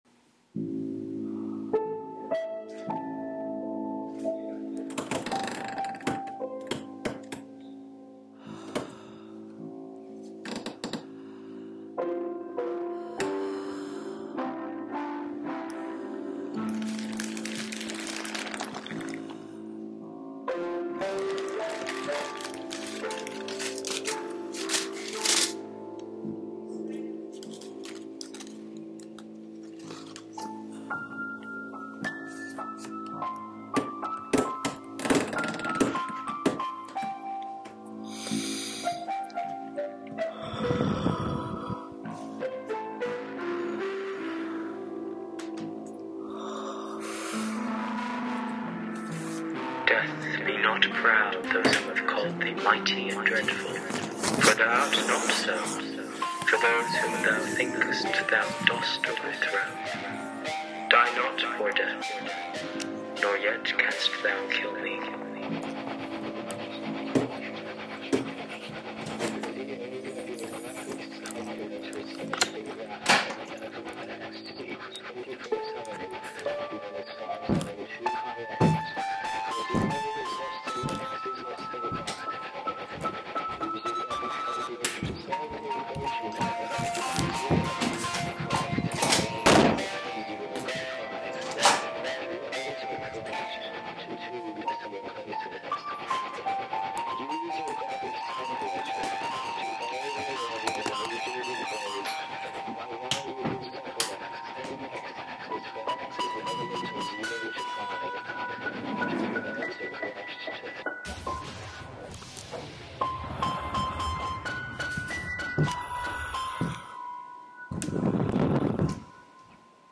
Piano, doorhinges, creaking chair, footsteps, books, incantation. Recorded on laptop.